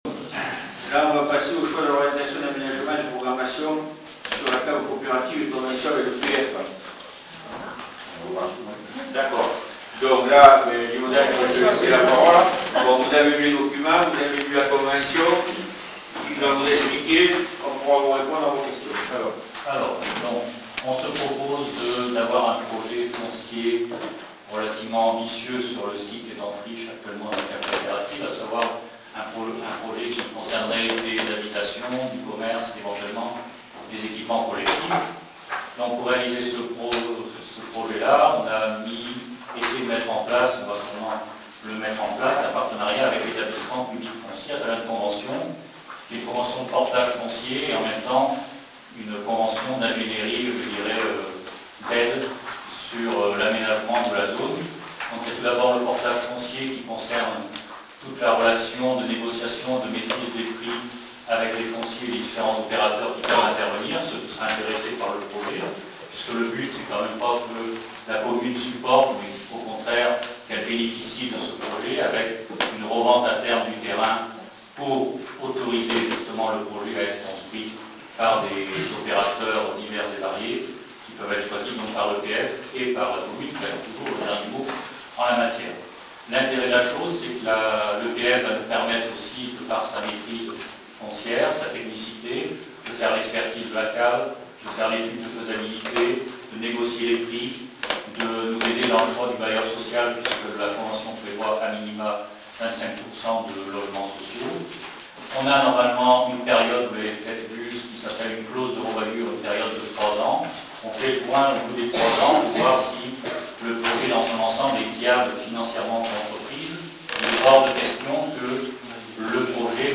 Le conseil municipal du 26 octobre s'est prononcé pour l'achat de la cave coopérative. Le débat en séance a été houleux.